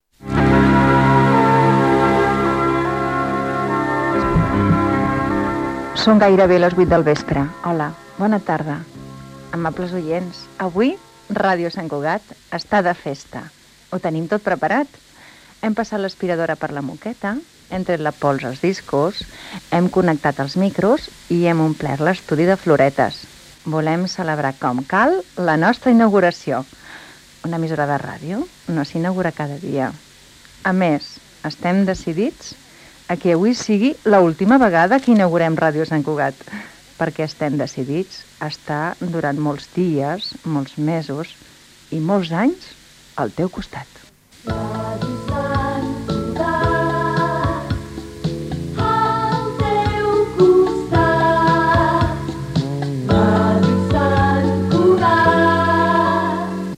Fragment de la primera emissió de Ràdio Sant Cugat, abans que fos emissora municipal, en la seva segona etapa.
FM